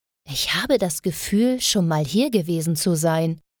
Stimme bearbeitet, Verbesserungsvorschläge?
Hi, ich bereite gerade Audioaufnahmen für ein Spiel an dem ich arbeite vor, ich habe diese kurze Aufnahme mal durch SSL Vocalstrip2 und dann in den Brickwalllimiter gelassen.